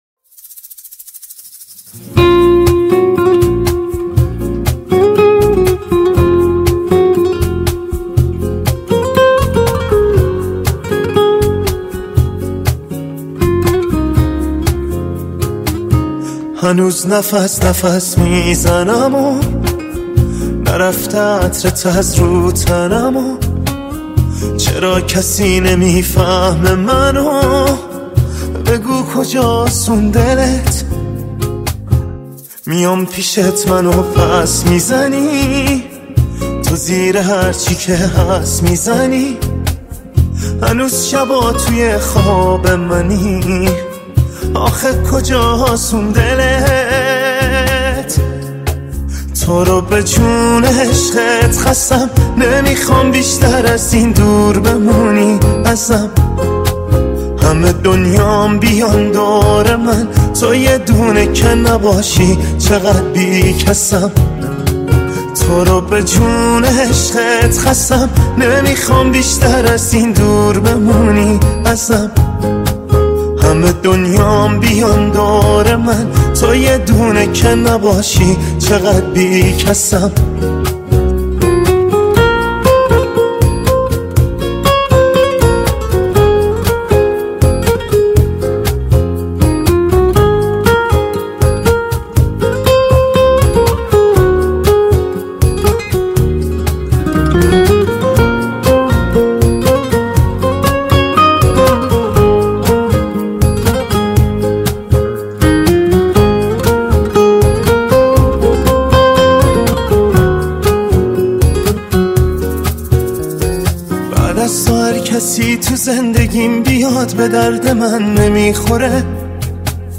ورژن آکوستیک